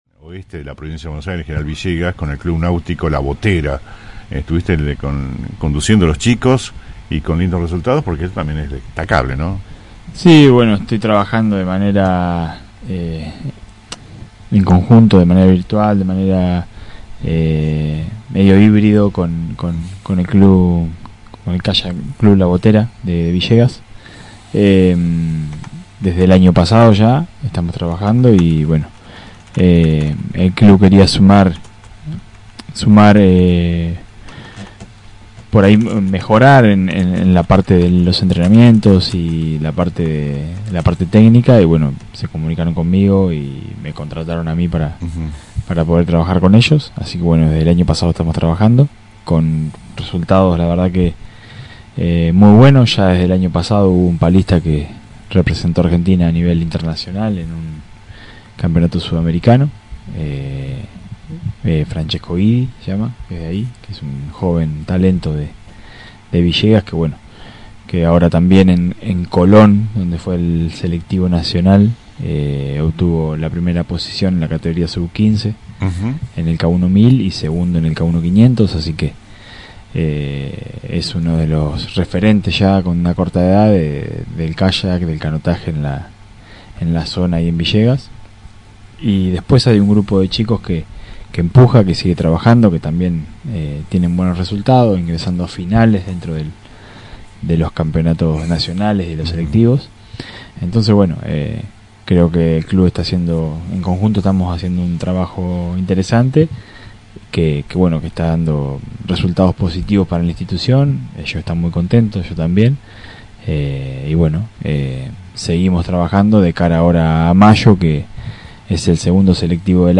estuvo en los estudios de la radio para conversar sobre los distintos eventos que estuvo asistiendo